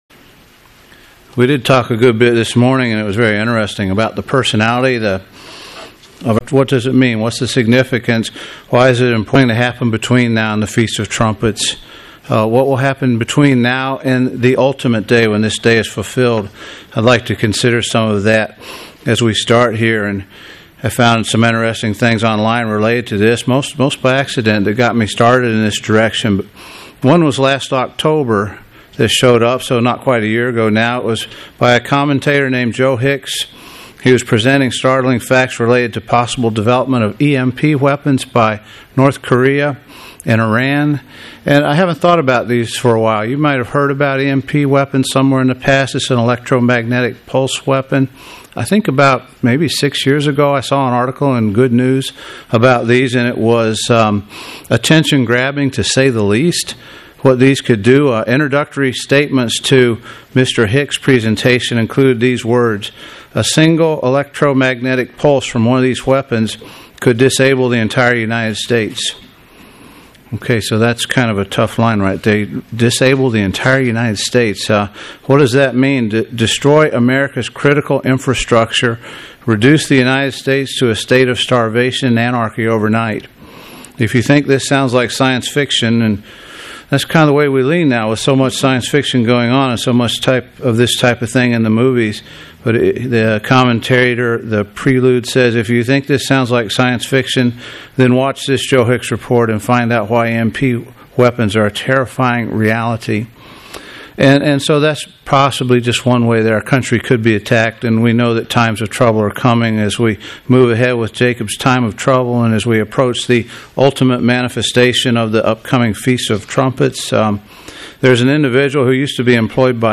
Given in Atlanta, GA
UCG Sermon Studying the bible?